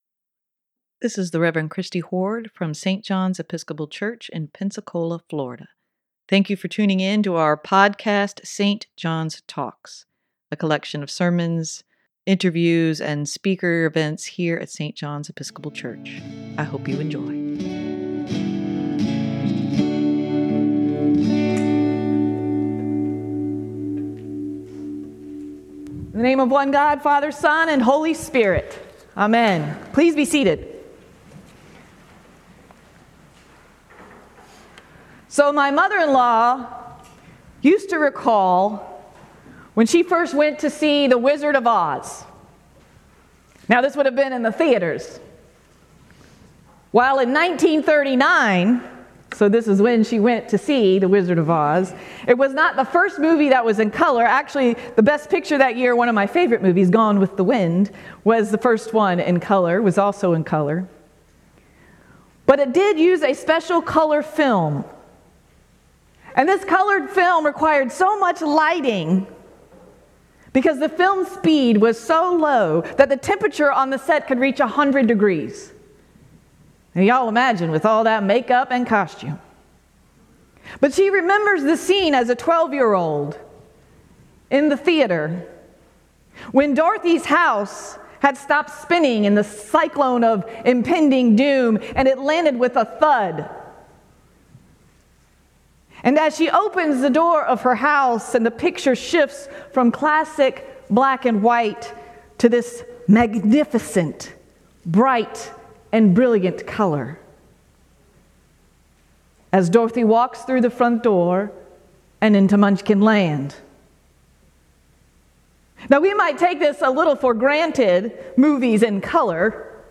Sermon for Feb. 19, 2023: What Happens After the Mountaintop Experience?
sermon-2-19-23.mp3